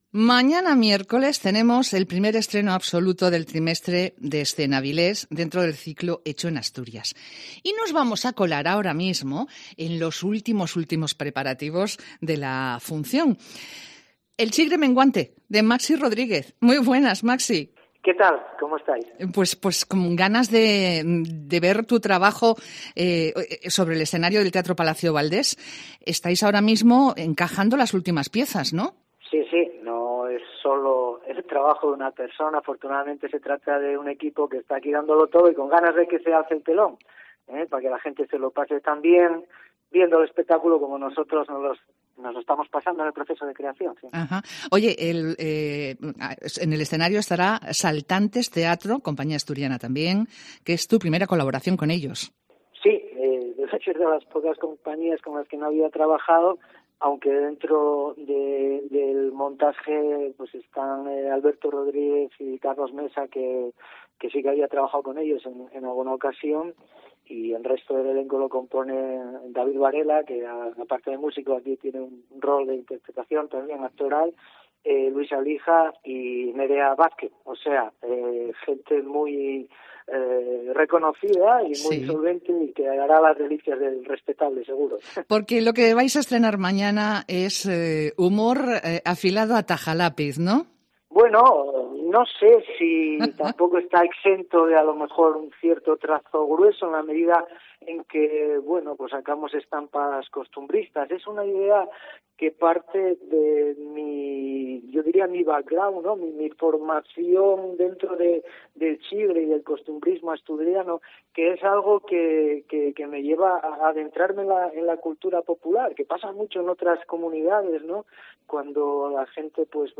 Entrevista con Maxi Rodríguez